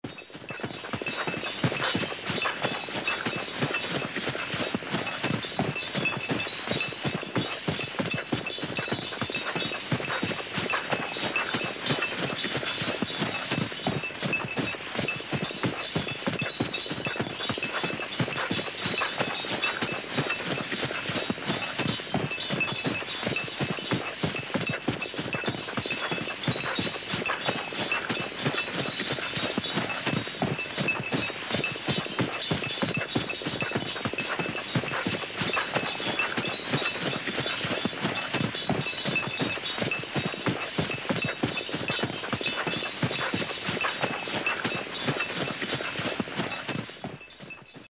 Лошадь с санями и бубенцом мчится по снегу